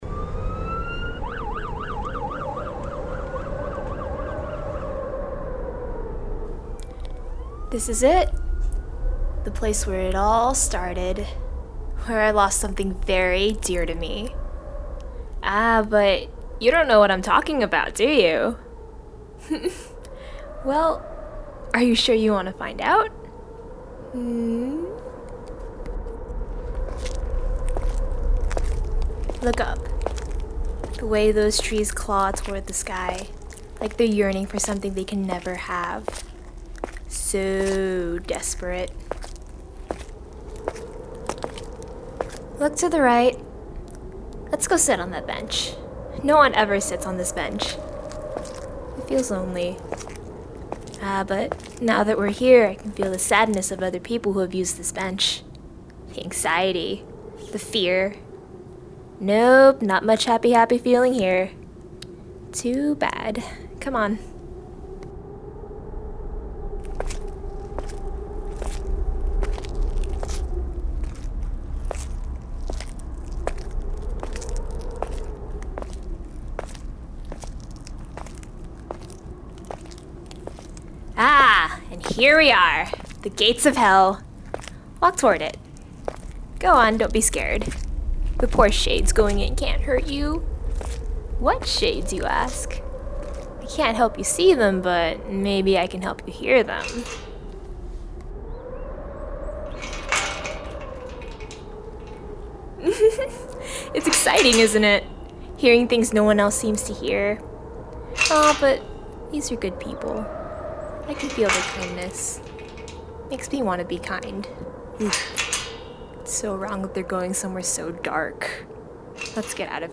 Audio Walk
Start location: Rose Walk, near High Street
Instructions: Begin at the very start of the Rose Walk. Walk forward when you hear the footsteps of the narrator.